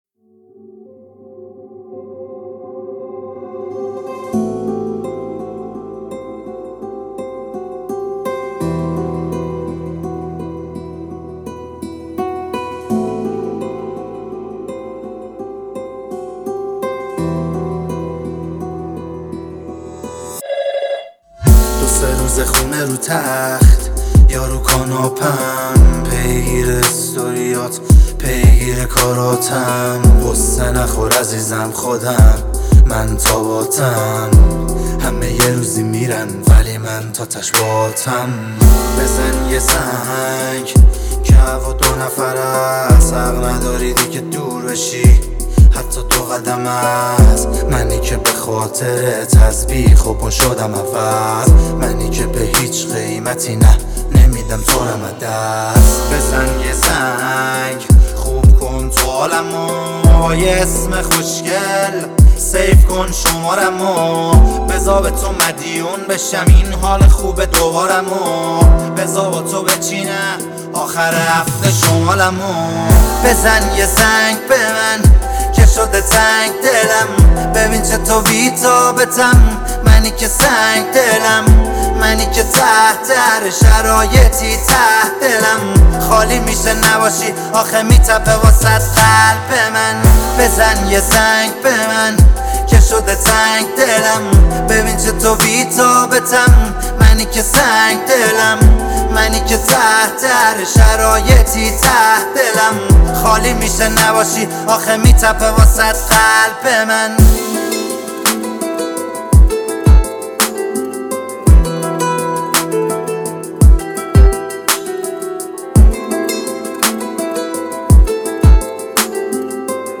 آهنگ عاشقانه